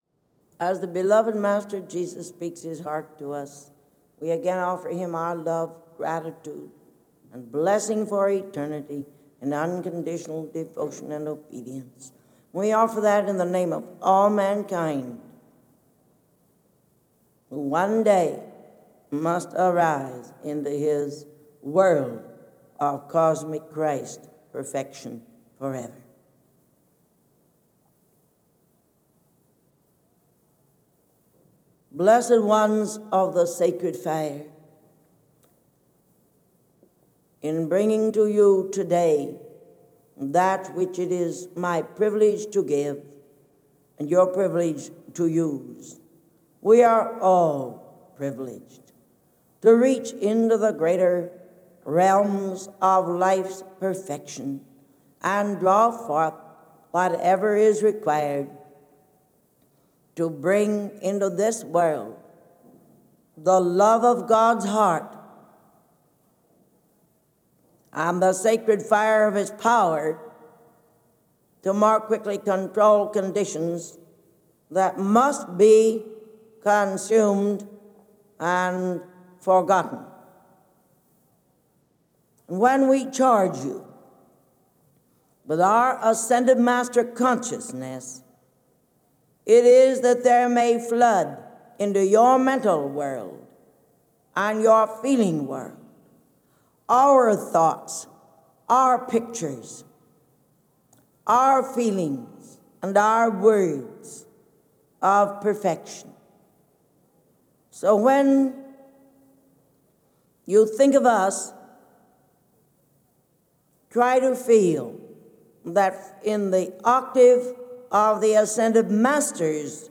Discourse by Jesus